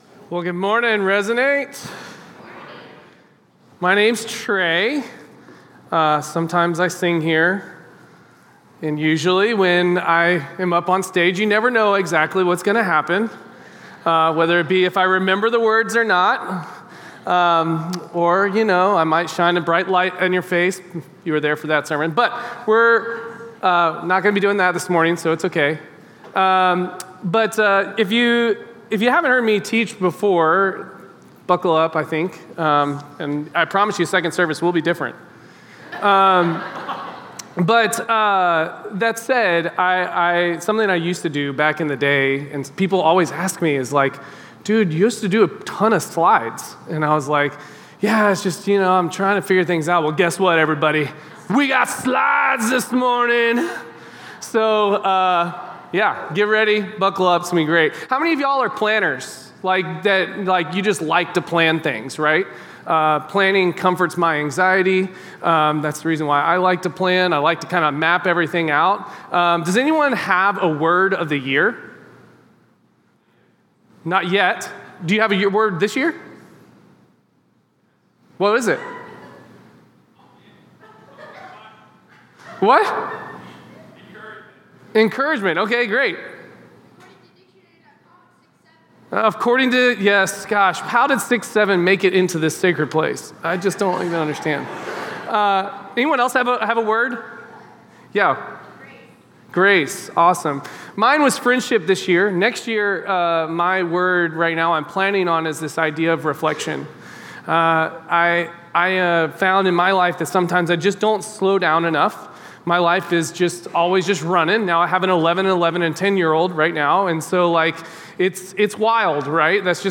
In this Advent sermon